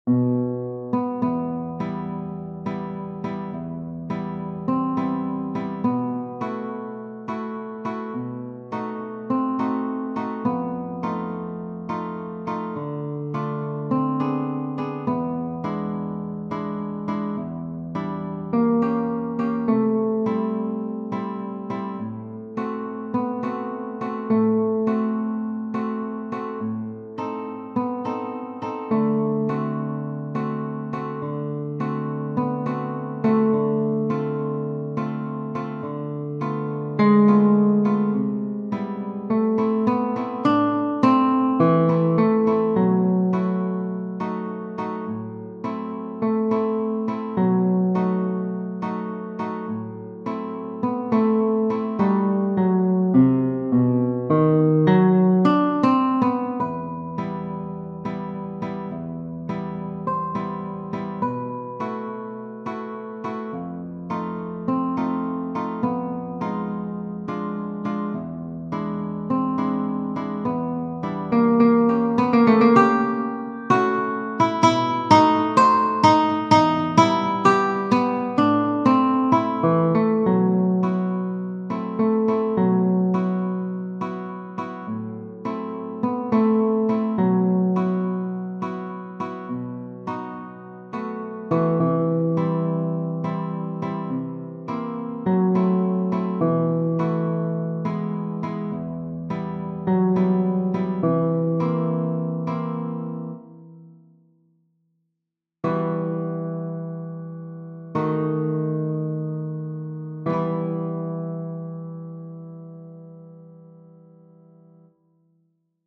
Chopin, F. Genere: Romantiche Il preludio op. 28 n. 4 in Mi minore fa parte della raccolta di 24 preludi per pianoforte scritti da Fryderyk Chopin fra il 1831 e il 1839.